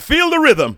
VOX SHORTS-1 0025.wav